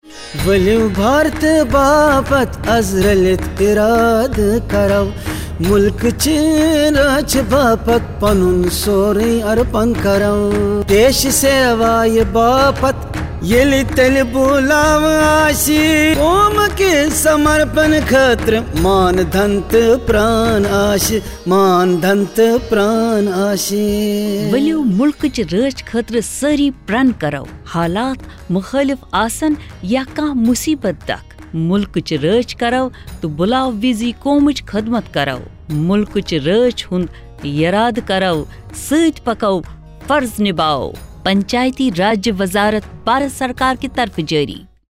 83 Fundamental Duty 4th Fundamental Duty Defend the country and render national services when called upon Radio Jingle Kashmiri